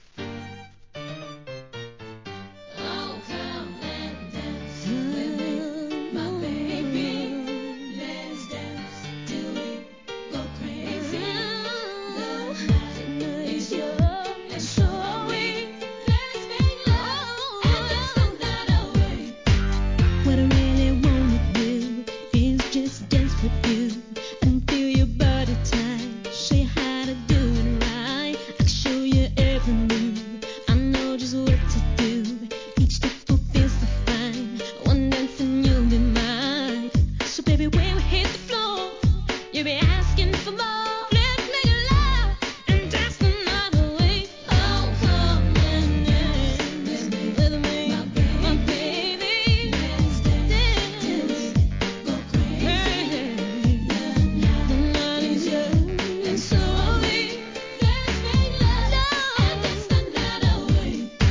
HIP HOP/R&B
タンゴ・リメイクでの2000年作品!!